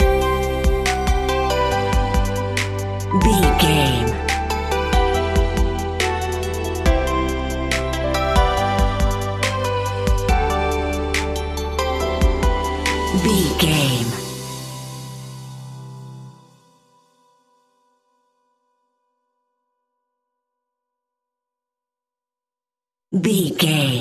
Ionian/Major
Slow
chilled
laid back
Deep
hip hop drums
hip hop synths
piano
hip hop pads